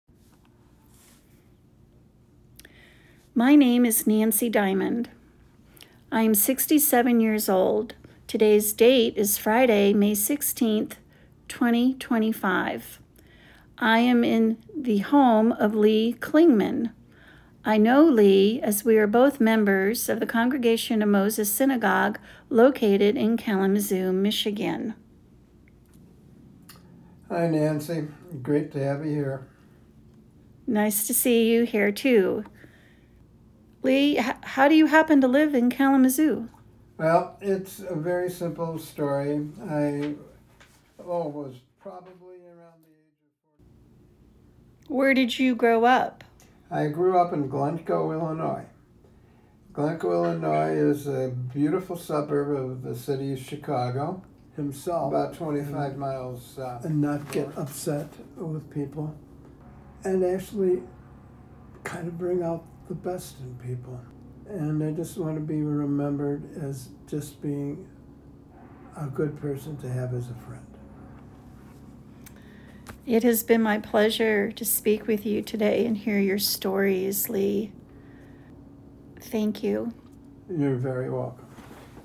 Sample audio from the CoM Oral History project